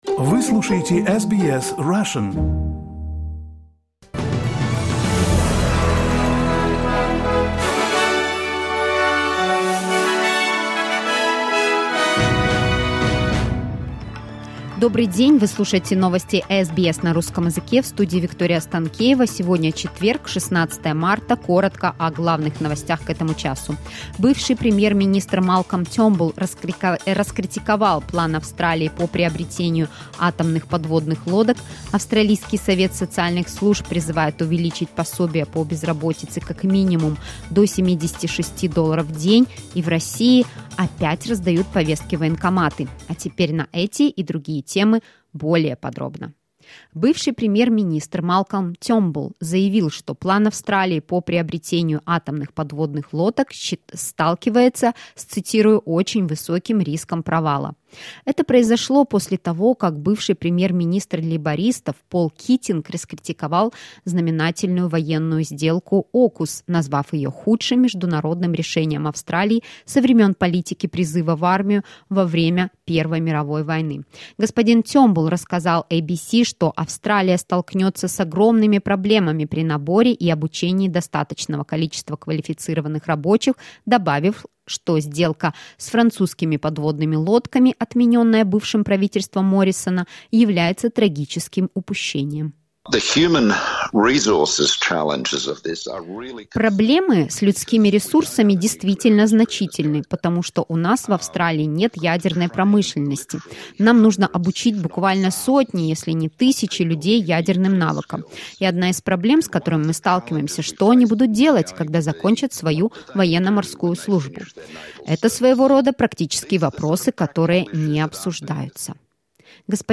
SBS news in Russian — 16.03.2023